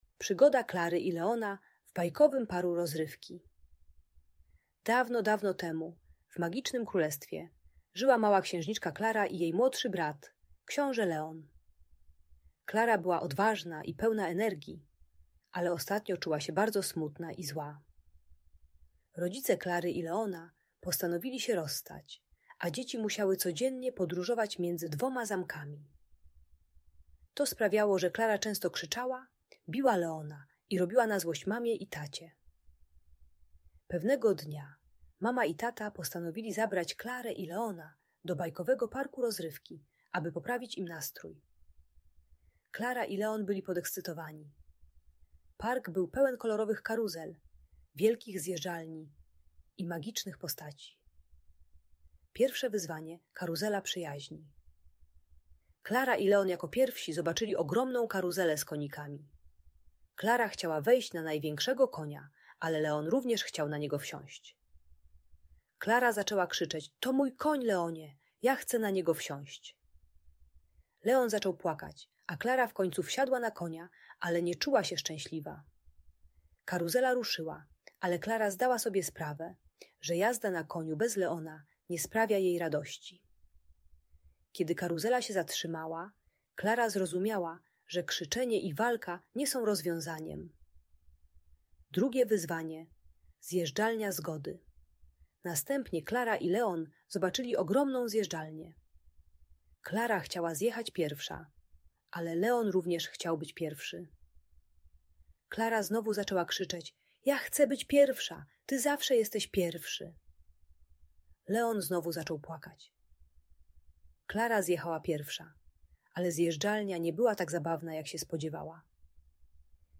Bajka dla dziecka które bije rodzeństwo po rozwodzie rodziców. Audiobook dla dzieci 4-7 lat o rozstaniu rodziców i radzeniu sobie z trudnymi emocjami. Uczy techniki współpracy zamiast krzyków i agresji wobec brata lub siostry.